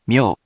We have our computer friend, QUIZBO™, here to read each of the hiragana aloud to you.
In romaji, 「みょ」 is transliterated as 「myo」which sounds sort of like 「myohh」.